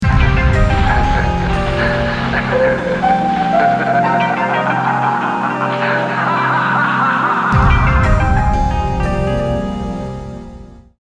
Tags: SF / パロディ / ループ / 怖い / 面白い / 高笑い